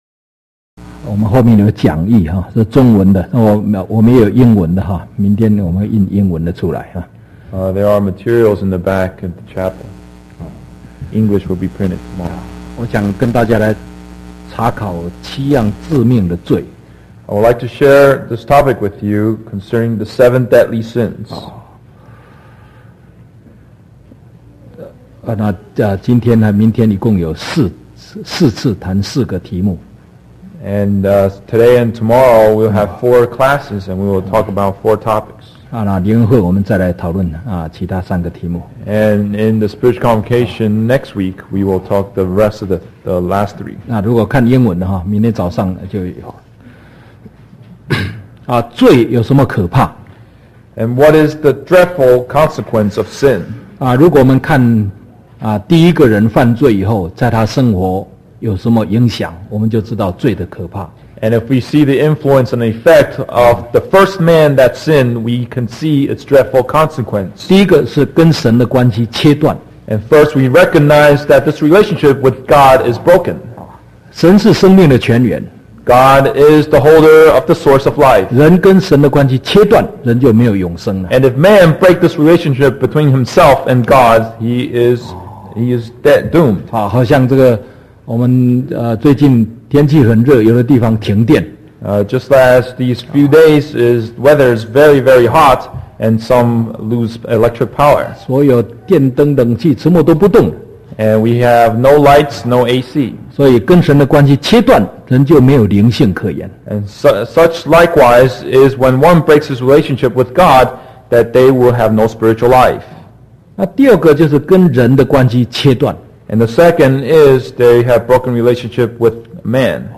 TJC True Jesus Church audio video sermons Truth Salvation Holy Spirit Baptism Foot Washing Holy Communion Sabbath One True God